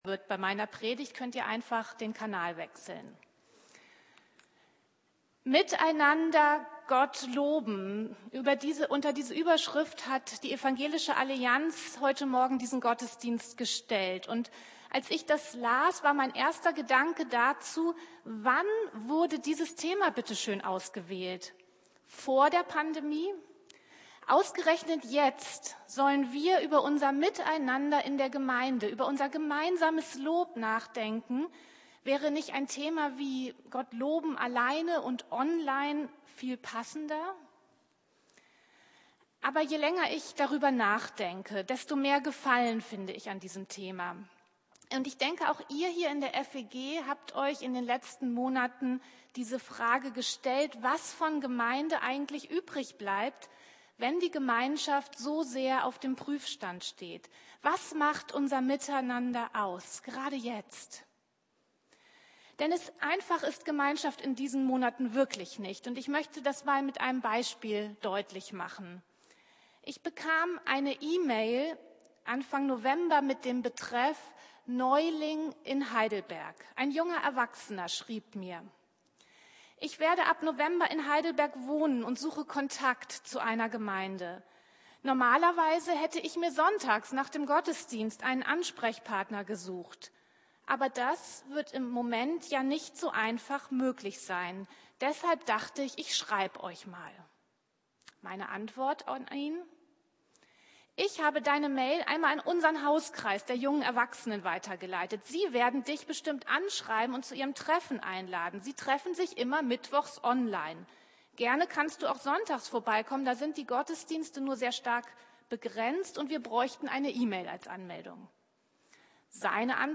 Miteinander Gott loben 17. Januar 2021 Predigt Briefe , Kolosser , Neues Testament Mit dem Laden des Videos akzeptieren Sie die Datenschutzerklärung von YouTube.
Abschlussgottesdienst zur internationalen Gebetswoche der evangelischen Allianz vom 17.01.2021.